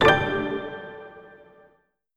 button-solo-select.wav